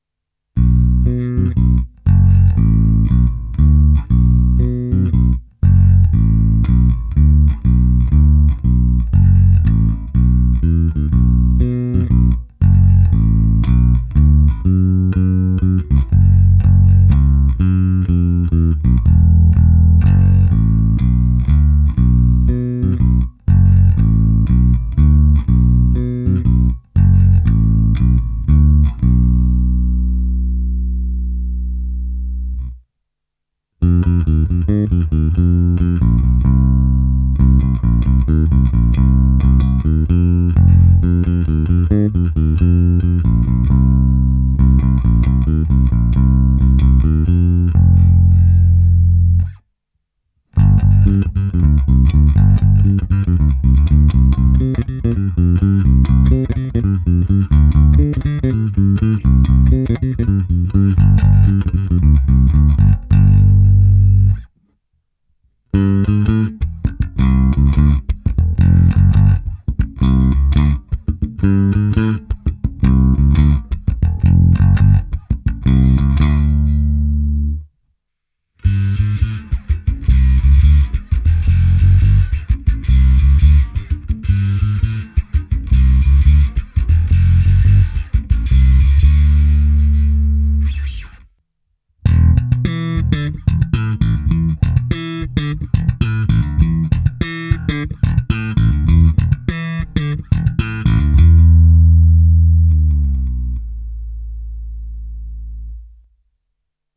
Zvonivý, vrčivý, průrazný, lahoda na slap.
Slap na oba snímače
Nahrávka přes Darkglass Microtubes X Ultra se zapnutou simulací reproboxu, kompresorem, v jedné části je použito i zkreslení. Hráno na oba snímače s plně otevřenou tónovou clonou.